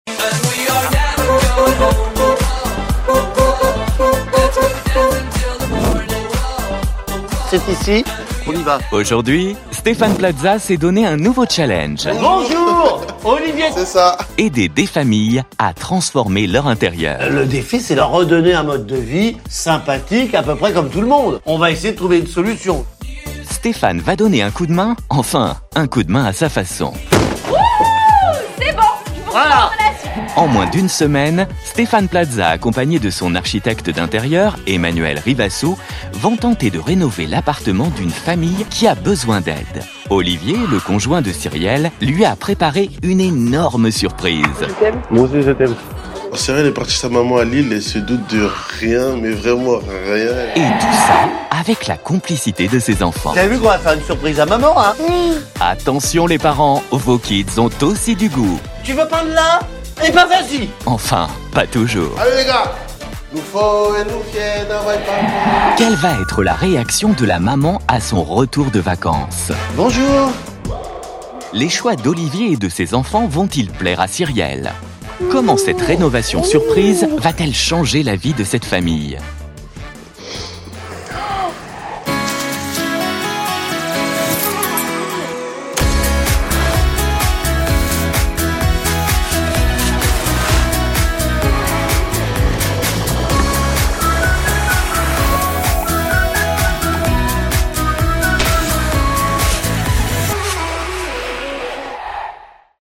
VOIX OFF émission
Sa voix a su traverser les époques sans jamais perdre de sa pertinence, de sa chaleur ni de son impact.